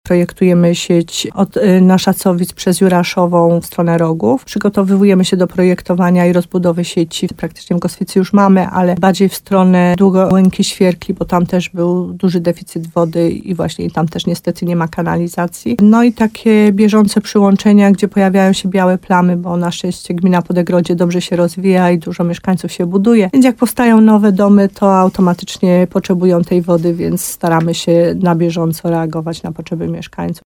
Jak powiedziała wójt Podegrodzia Małgorzata Gromala, planowane są też inne inwestycje.